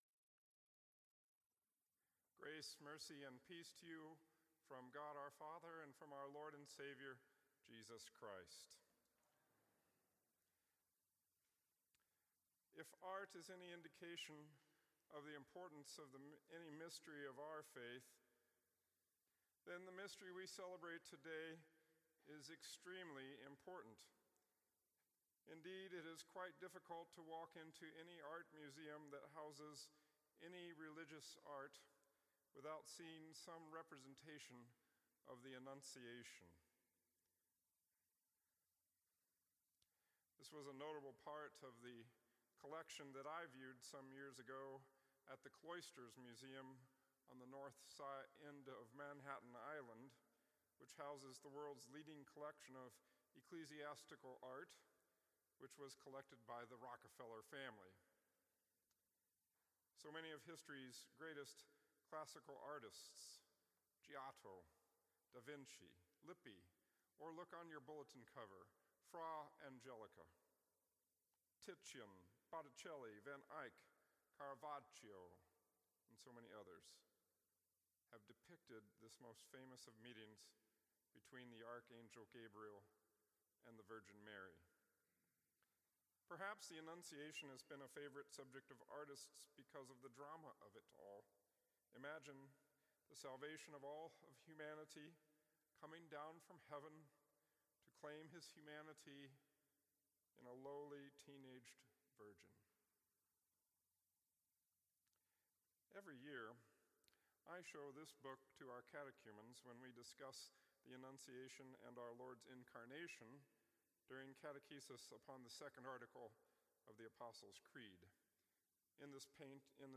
The Feast of the Annunciation of Our Lord